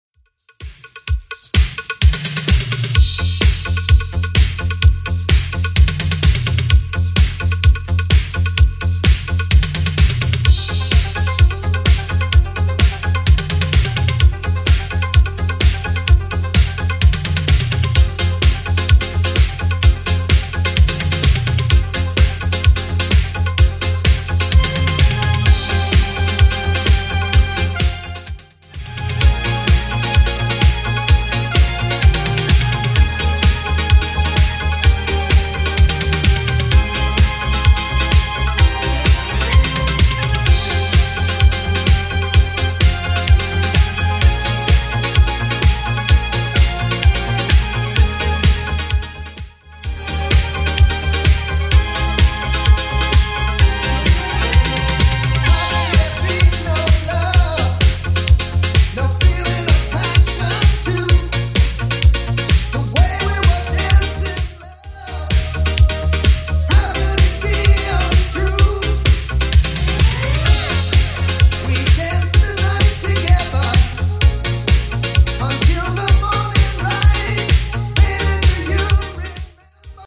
Genre: 90's